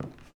default_wood_footstep.2.ogg